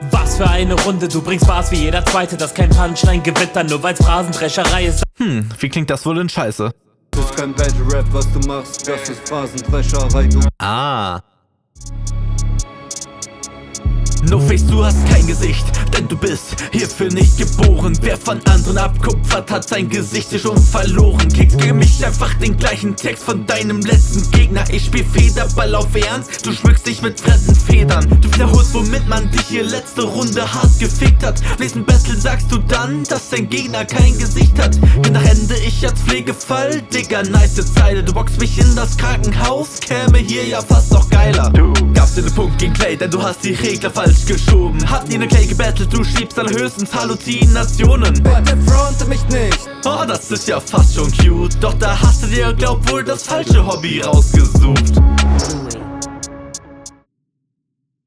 Intro war nicht nötig, aber nimmt der Runde auch nichts.
Intro trifft gut.